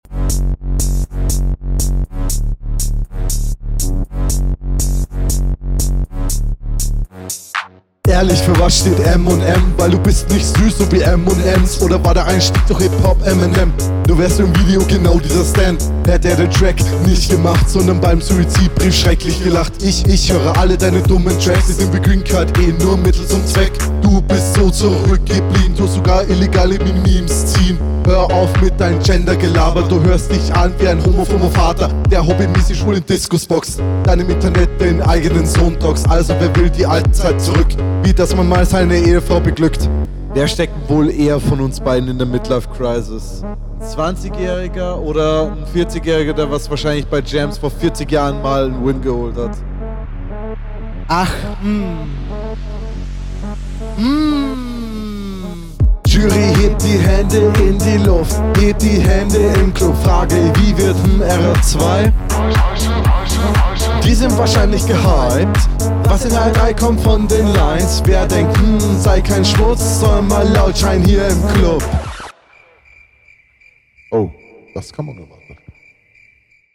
puh. das mal n beat. find ich freaky. deine formulierungen ziehen hier aber öfter die …